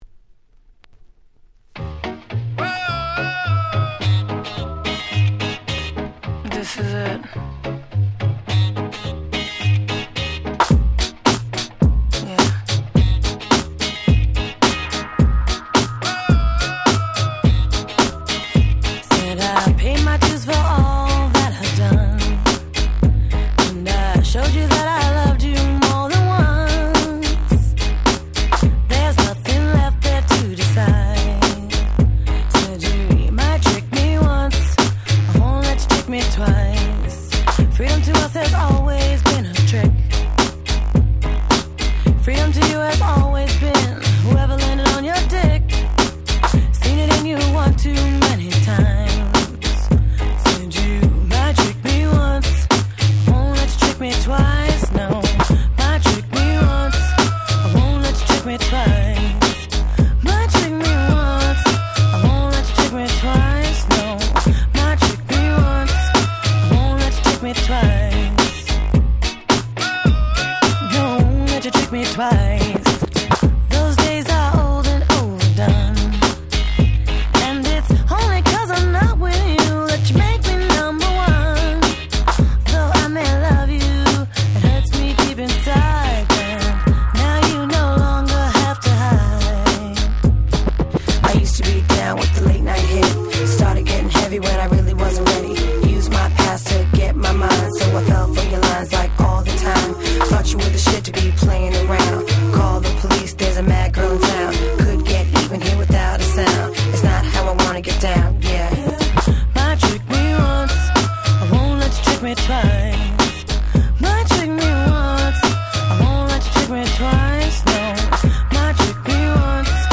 04' Super Hit R&B !!
軽快なBeatでつい体が動いてしまいます♪